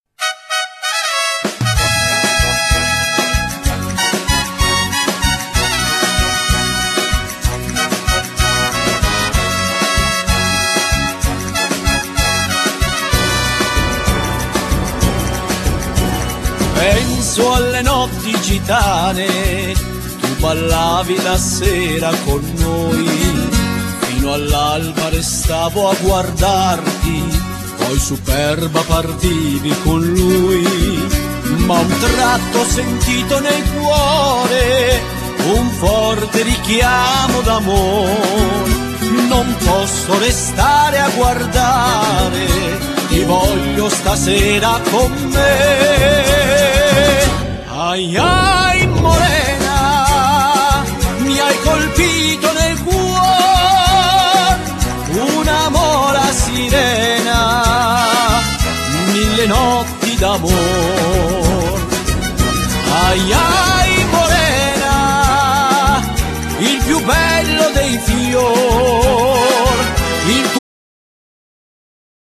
Genere : Liscio